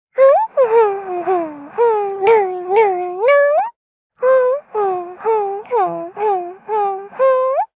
Taily-Vox_Hum_jp.wav